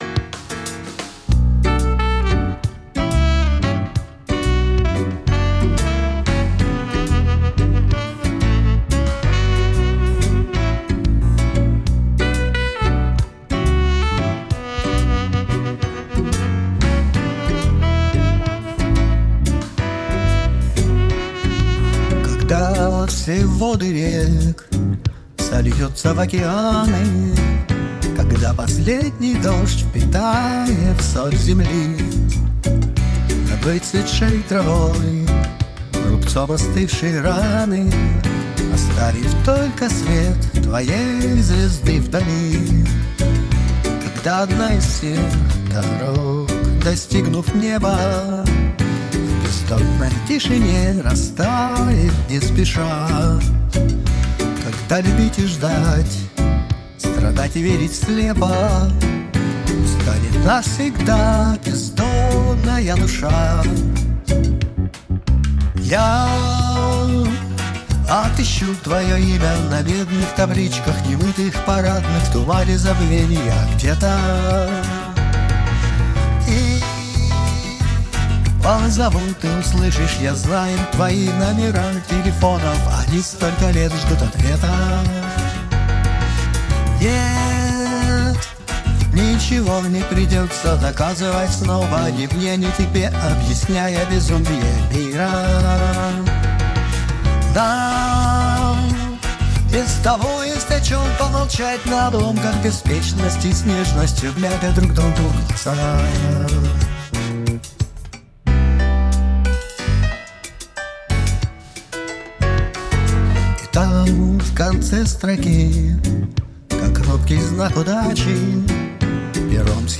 Вокал вытащить толком неоткуда. Пара нейросеток с этим ничего поделать не могут.